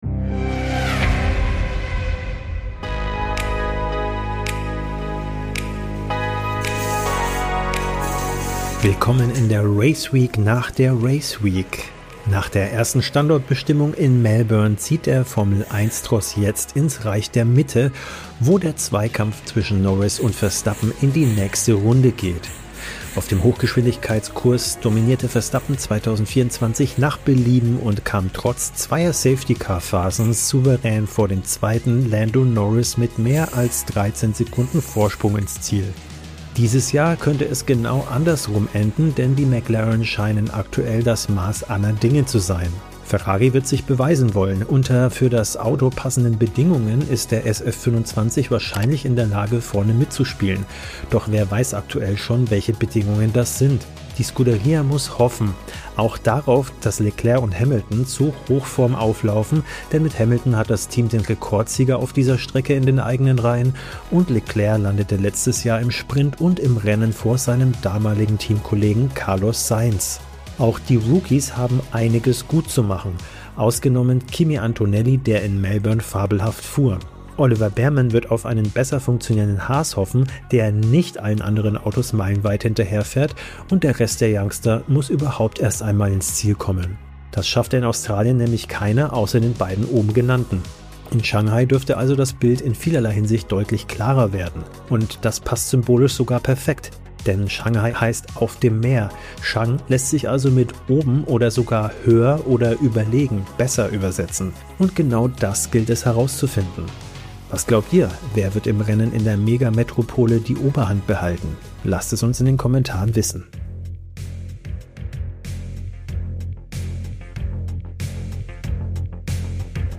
Dein Formel-1-Talk